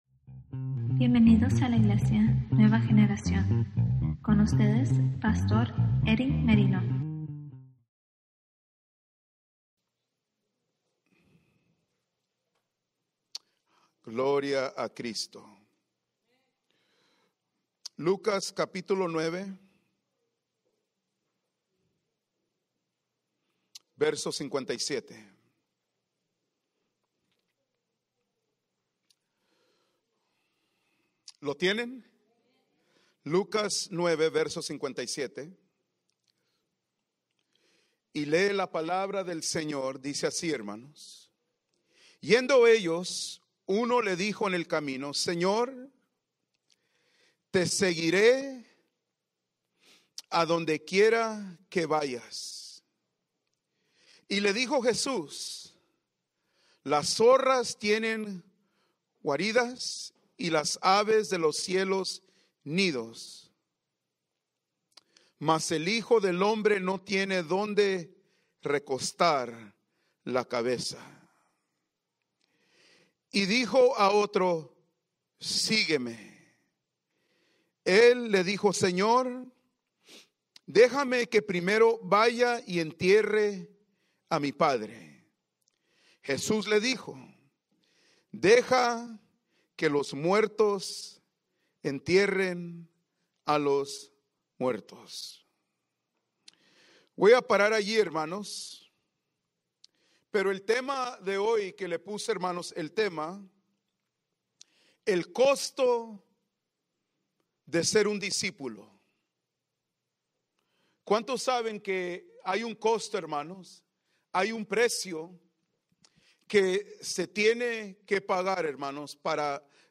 Sermons | New Generation Church